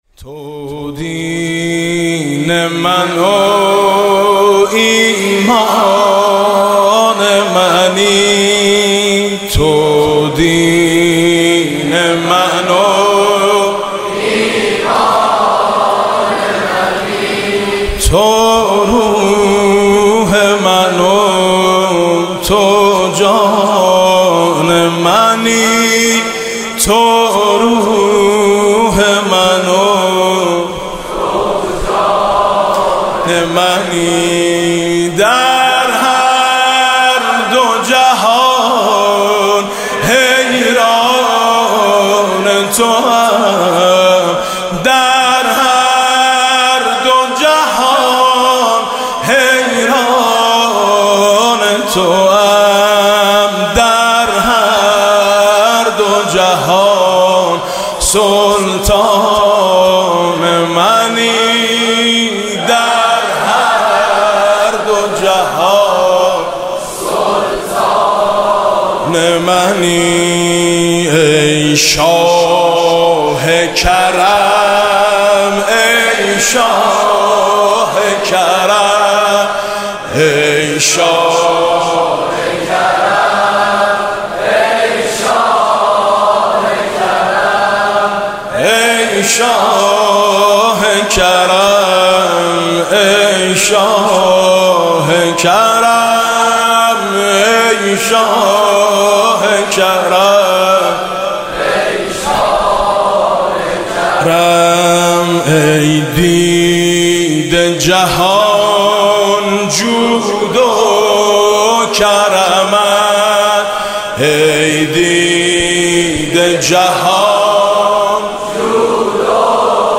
«میلاد امام رضا 1393» مدح: ماییم و خرابات تو رضا